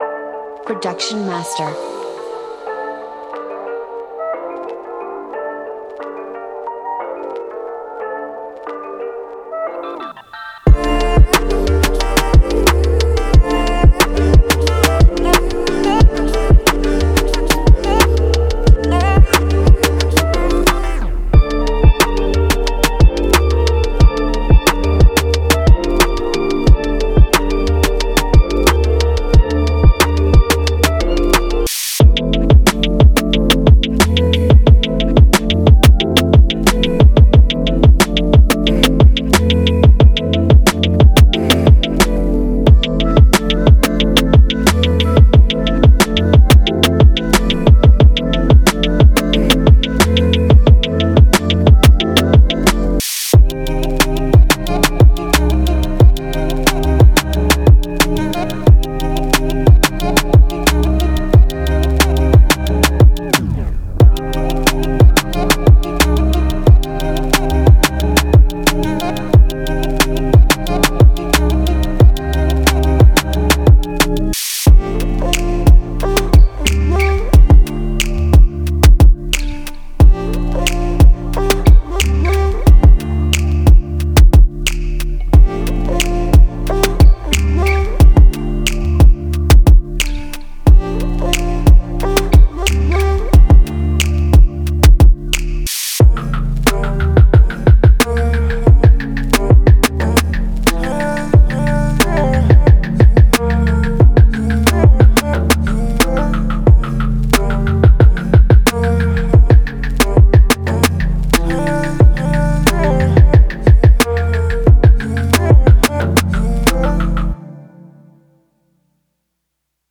24 Bit WAV Format 100% Royalty-Free 80-90BPM